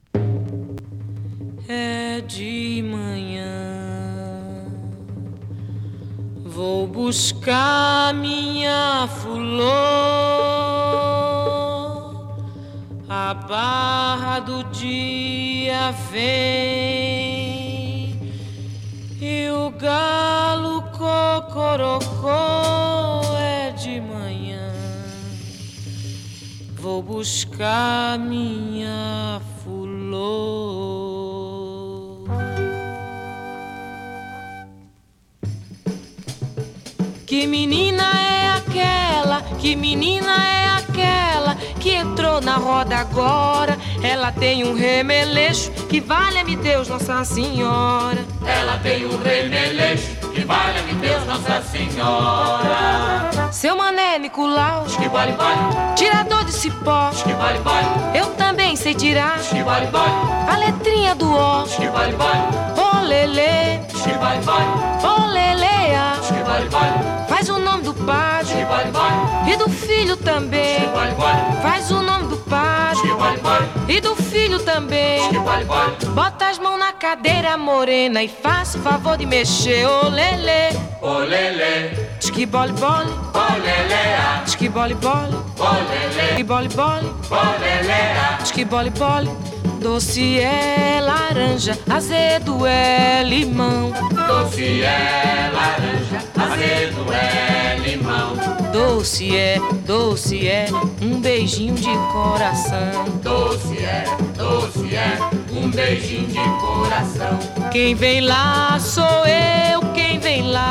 サンバ ジャズボーカル
ふくよかな温もりを感じさせるサンバ・ジャズボーカルの
＊時おり軽いパチ・ノイズ。
LP